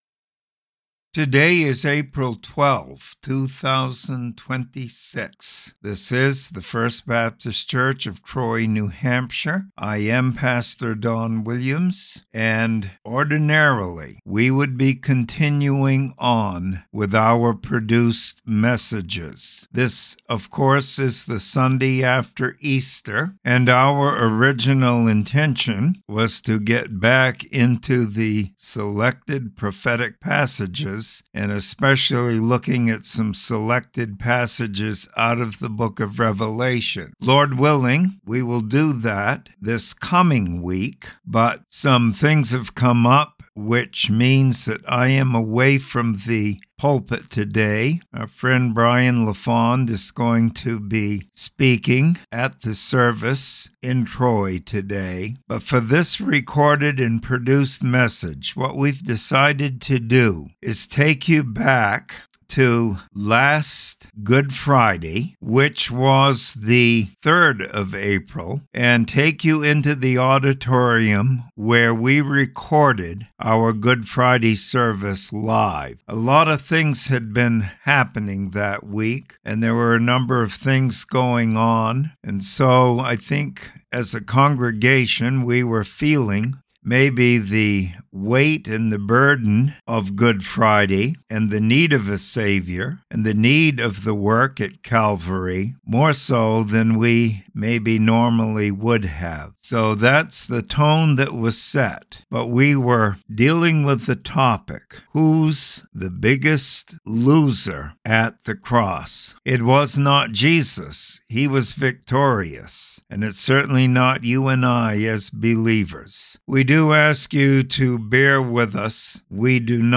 Good Friday Message recorded live.